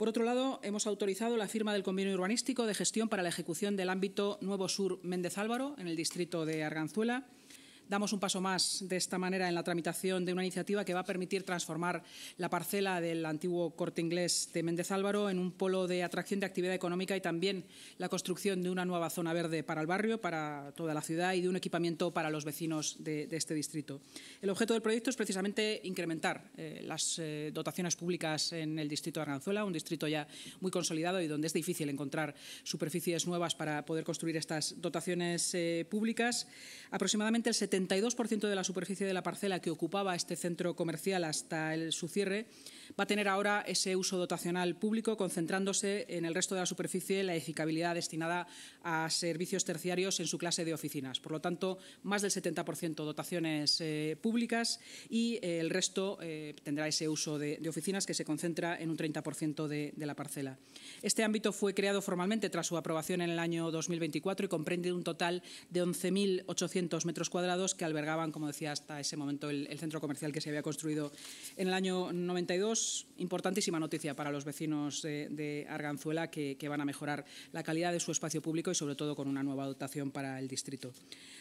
La Junta de Gobierno municipal ha autorizado hoy la firma del convenio urbanístico de gestión para la ejecución del ámbito ‘Nuevo Sur-Méndez Álvaro’ en Arganzuela. De esta forma, el Ayuntamiento da un paso más en la tramitación de una iniciativa que permitirá la transformación de la parcela que ocupaba el Corte Inglés de Méndez Álvaro en un polo de atracción de actividad económica, además de la construcción de una nueva zona verde y de un equipamiento para los vecinos de este distrito, según ha informado la vicealcaldesa y portavoz municipal, Inma Sanz, en la rueda de prensa posterior.